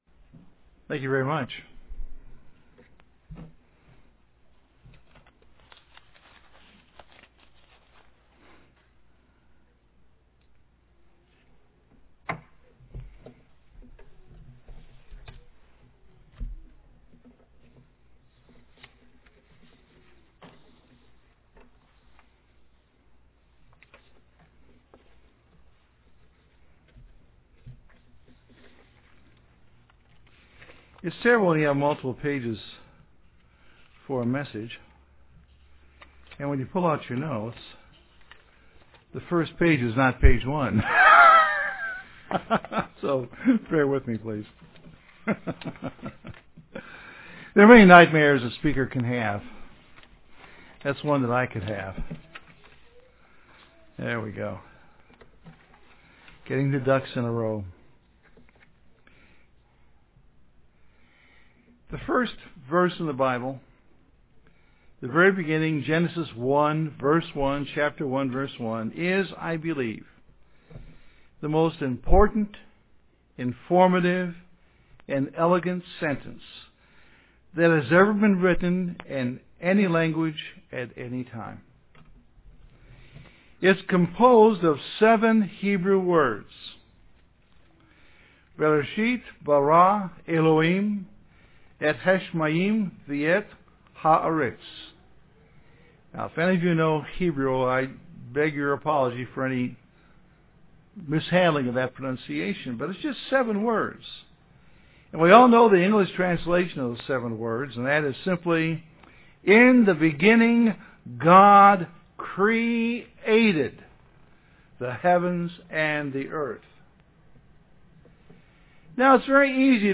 Given in Rome, GA
UCG Sermon Studying the bible?